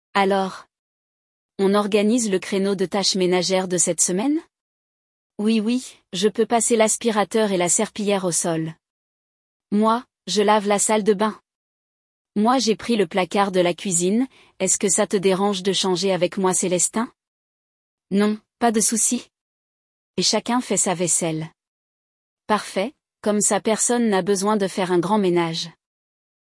Diálogo